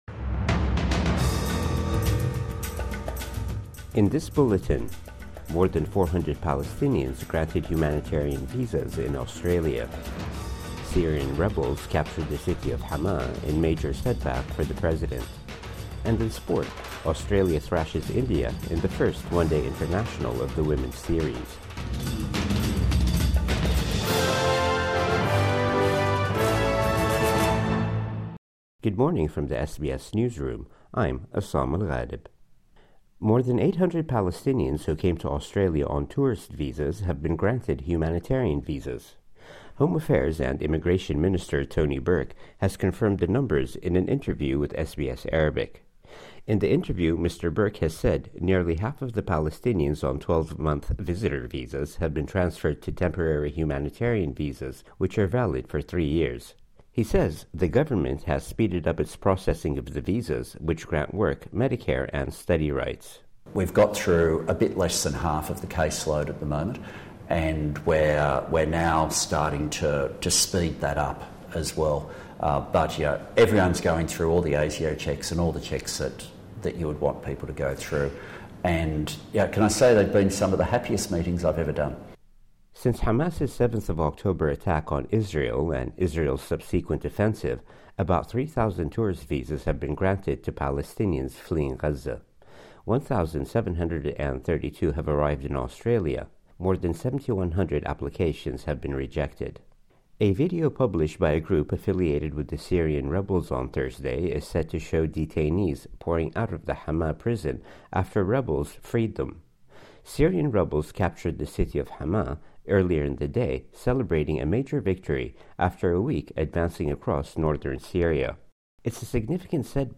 Morning News Bulletin 6 December 2024